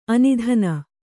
♪ anidhana